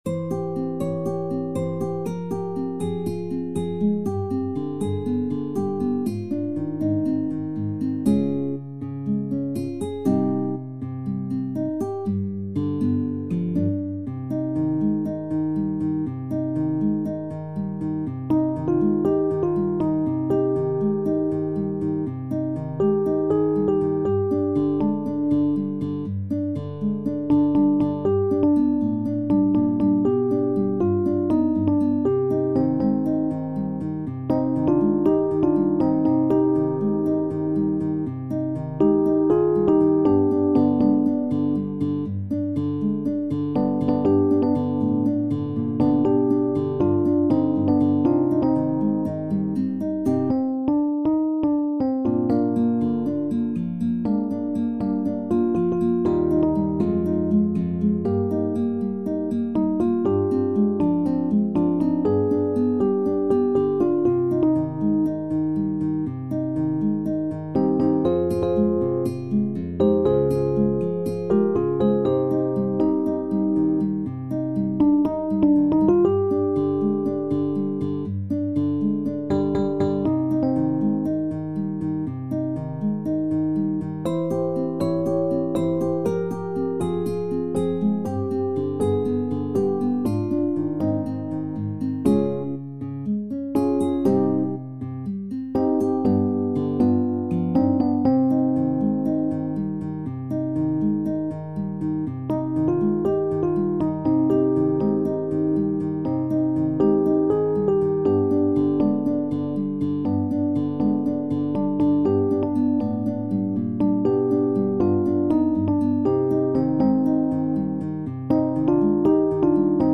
SSAA mit Klavier
Ein kämpferisches Lied